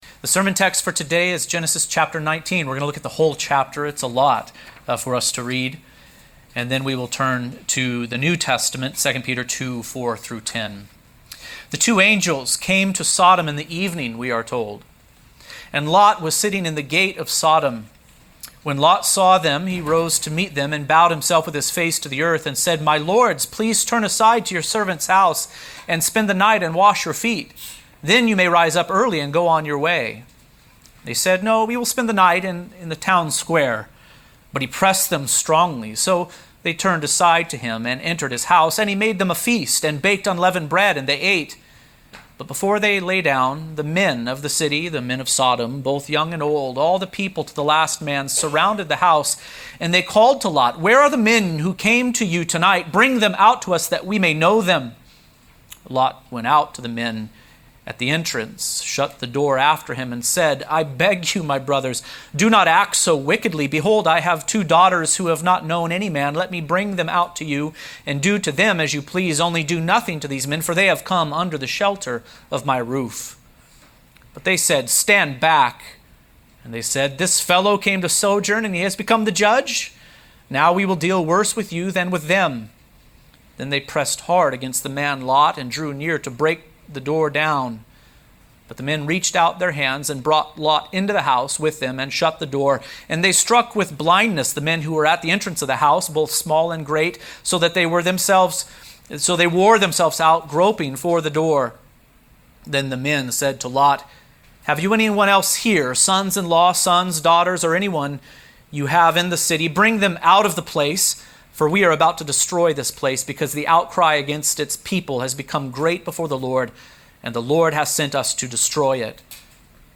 The Destruction Of Sodom | SermonAudio Broadcaster is Live View the Live Stream Share this sermon Disabled by adblocker Copy URL Copied!